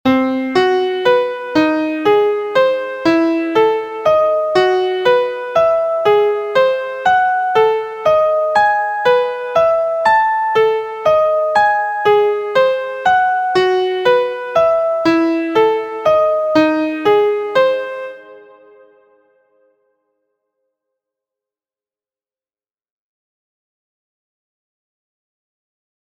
• This example shows both permutations of a 3 note modal sequence in C Lydian#2#5 scale (6th mode of Harmonic Major) using 3rds ascending and descending one octave.